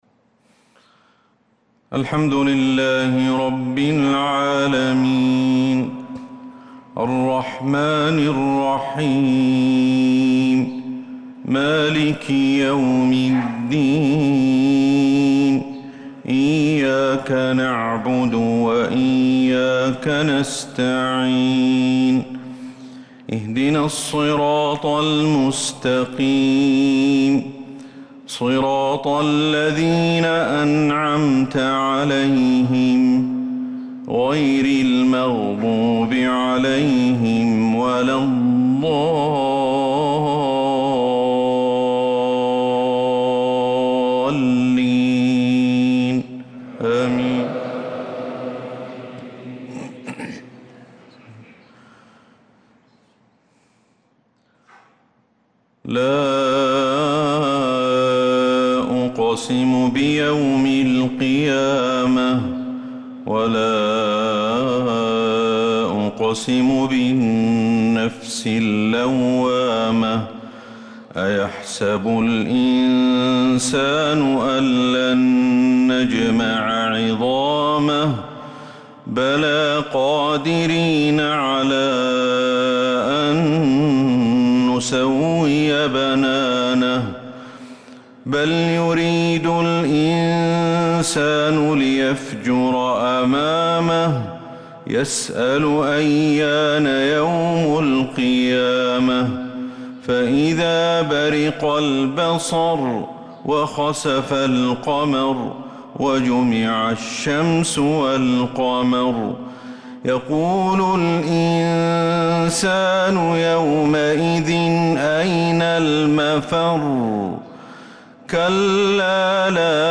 صلاة الفجر للقارئ أحمد الحذيفي 22 ذو القعدة 1445 هـ
تِلَاوَات الْحَرَمَيْن .